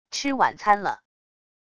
吃晚餐了wav音频生成系统WAV Audio Player